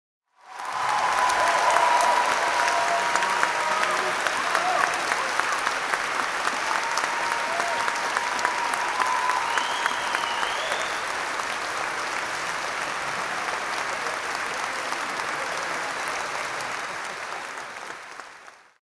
Cheering Crowd With Applause Sound Effect
Description: Cheering crowd with applause sound effect. Hundreds of people cheer, whistle, scream, and applaud inside a hall. Perfect crowd reaction sound effect for concerts, events, and live performances.
Cheering-crowd-with-applause-sound-effect.mp3